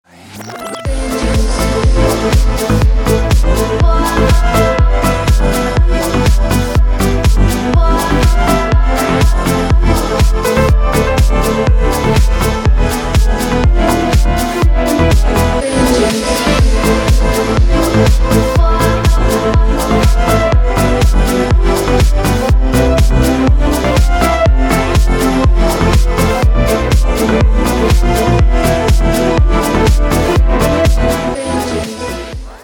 • Качество: 192, Stereo
dance
house